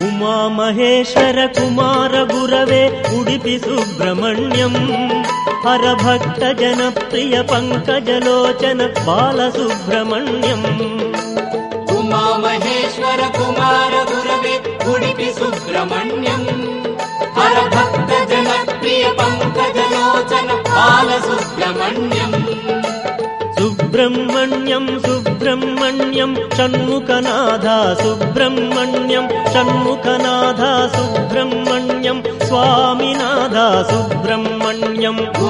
peaceful and devotional
best flute ringtone download | bhakti song ringtone